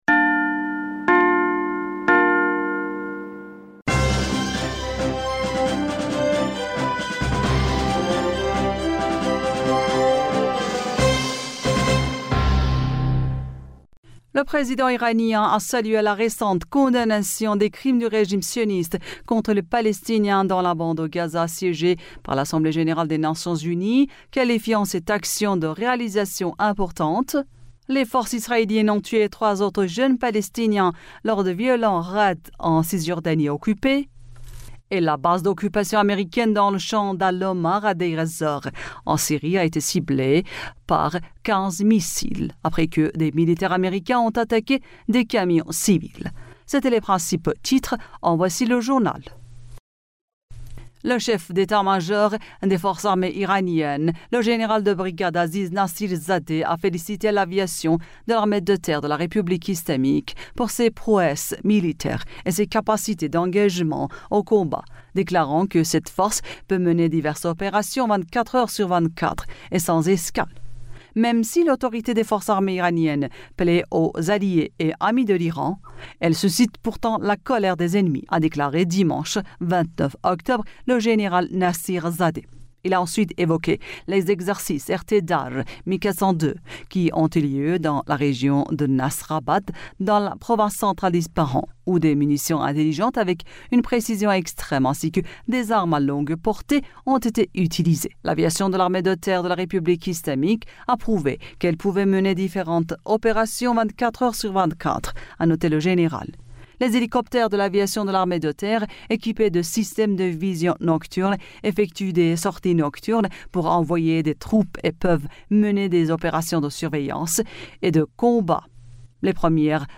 Bulletin d'information du 30 Octobre 2023